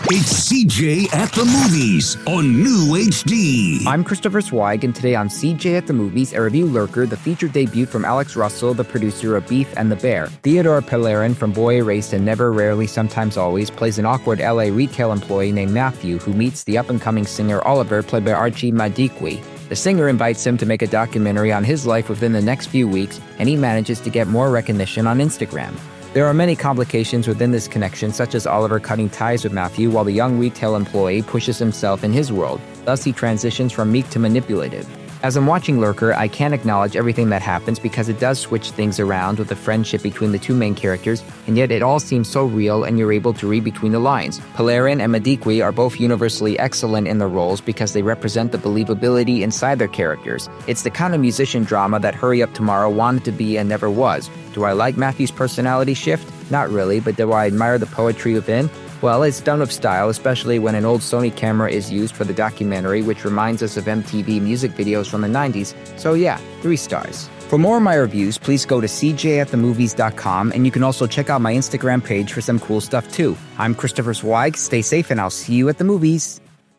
reviews five dangerous movies on the air.